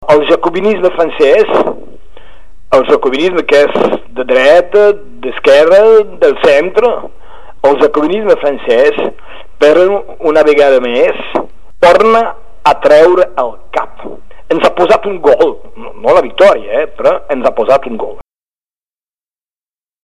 • Entrevista amb el batlle de Perpinyà i senador de la UMP sobre el veto del senat francès al reconeixement del català a la constitució